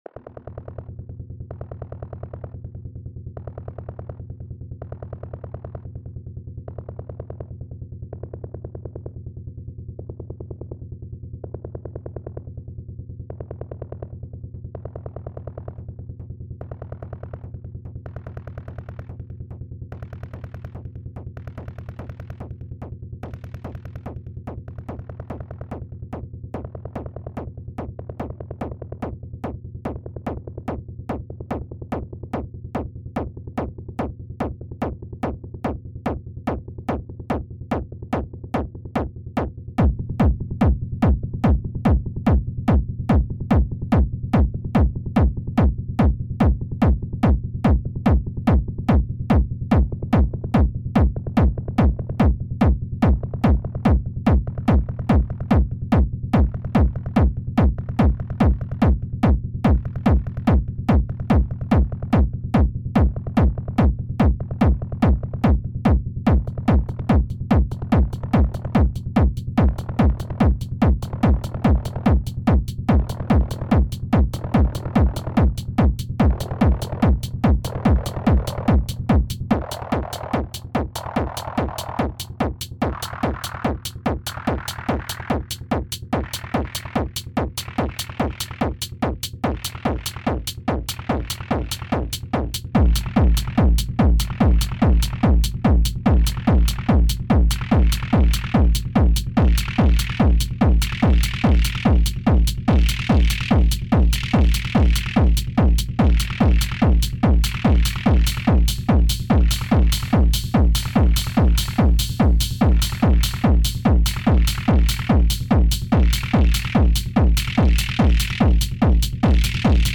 Here is the something I could contribute… just got my machine yesterday after it being stuck in Oude Meer for days… only had about 30 minutes with the machine before life took it away from me, but I am already very much wanting to with it again… just a quick loop… playing around with knobs while it runs, nothing special but really enjoying the machine… hope you enjoy…